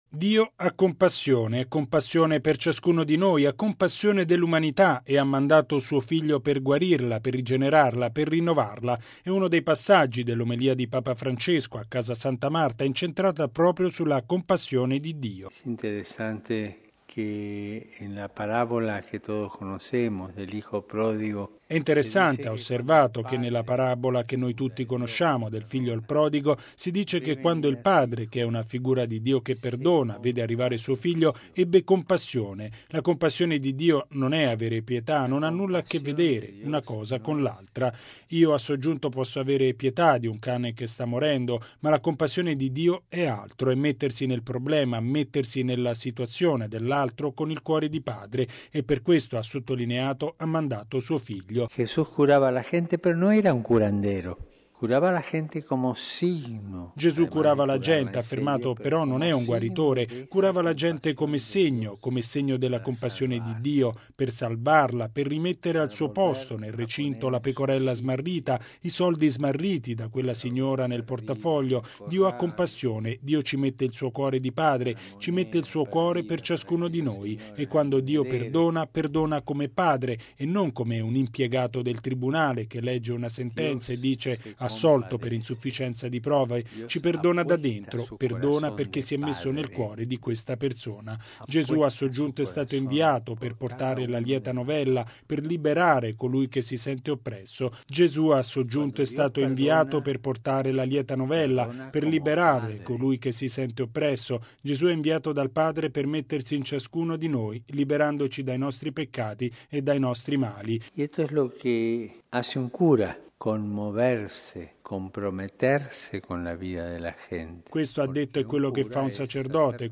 Un buon sacerdote sa “commuoversi” e “impegnarsi nella vita della gente”. E’ uno dei passaggi dell’omelia mattutina di Papa Francesco a Casa Santa Marta, pronunciata in spagnolo.